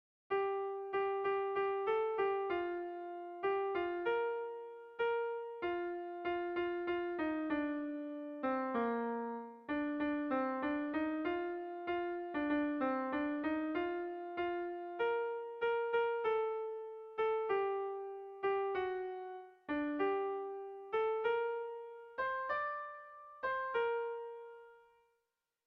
Bertso melodies - View details   To know more about this section
Irrizkoa